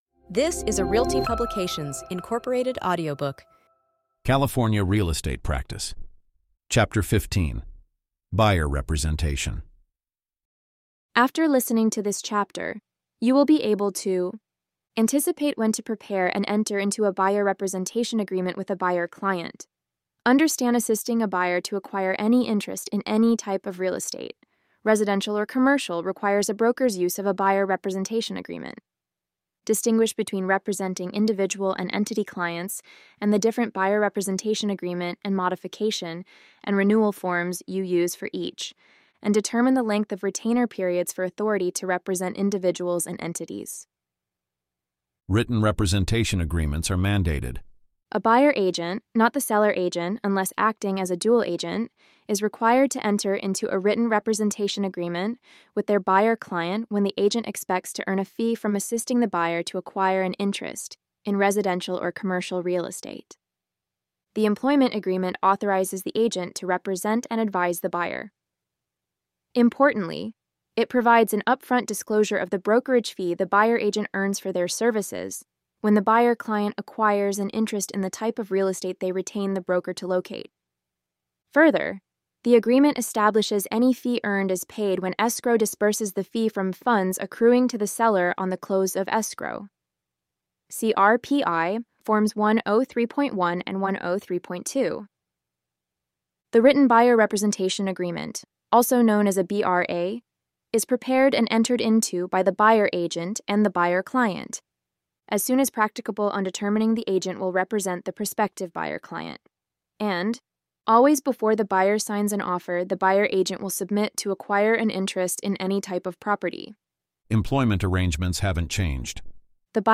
Follow along with an audio reading of this article adapted as a chapter from our upcoming Real Estate Practice course update.